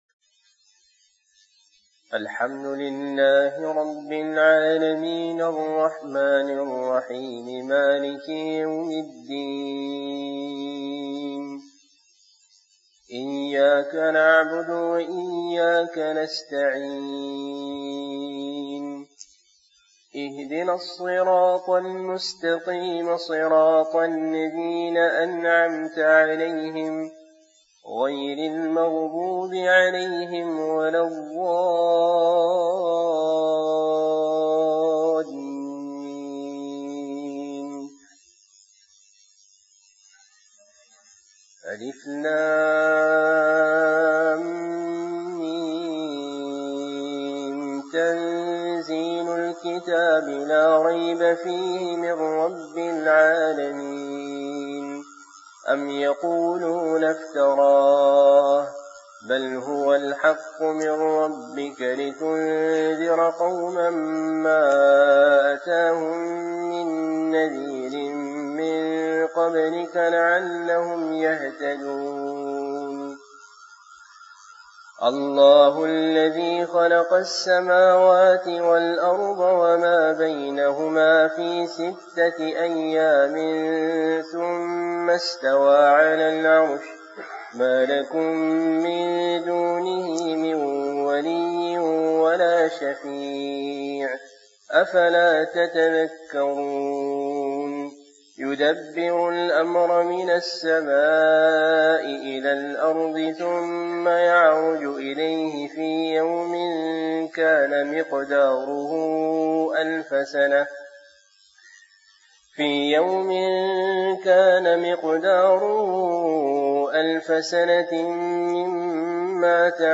Fajr, Qiraat And Dua